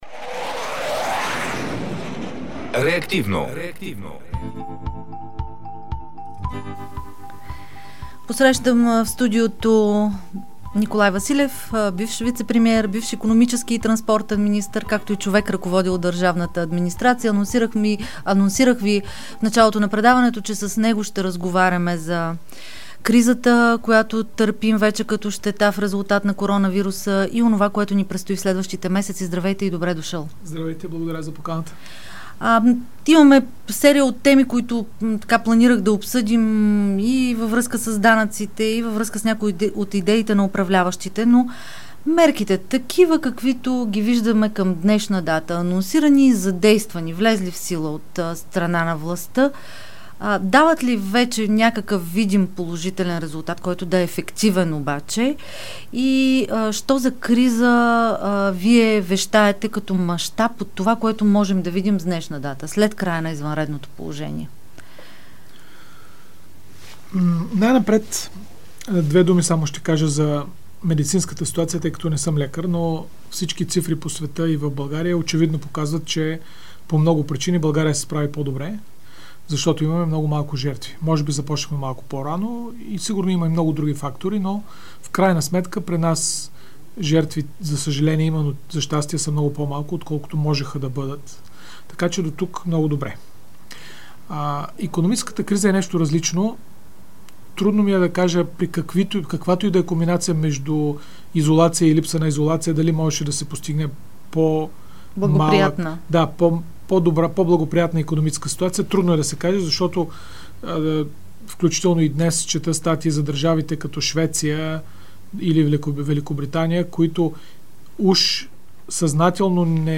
Икономиката пада и приходите намаляват, но многомилиардните разходи в бюджета си остават, заяви в предаването на Дарик “Реактивно” икономистът и бивш вицепремиер Николай Василев. По думите му, правителството трябва да съкрати разходи за ненужни обществени поръчки, автомобили, ПР услуги.